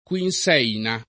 [ k U in S$ ina ]